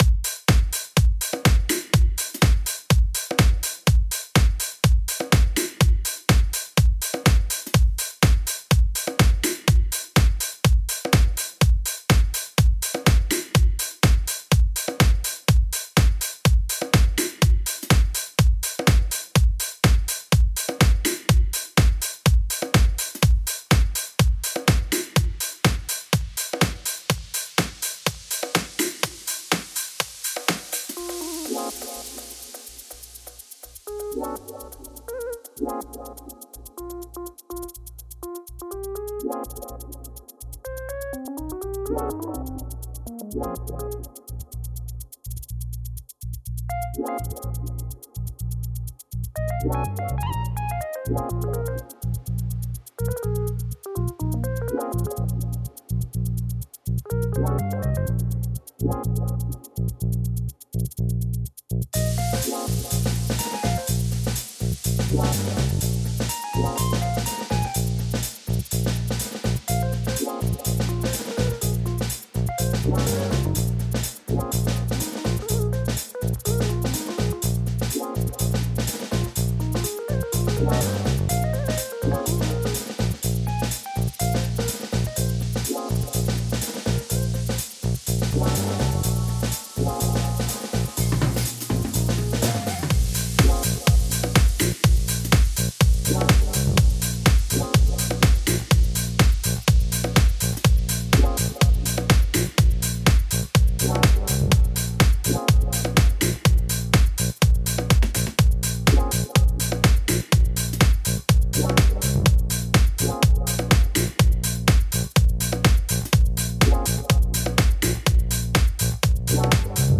Deep House Mixing Critique - Blackout Music
Hey guys, I'm new to the forum here and have been producing electronic music in my home for the last 3 years or so.
All synth patches have been created by me with the help of the trusty soft synth from Native Instrument's called Massive.
The bass guitar, really the core of the low-end of this song, is a pre-bass sampler created by Scarbee that runs through Kontakt 5.
The audio peaks on average around -1.5 dB.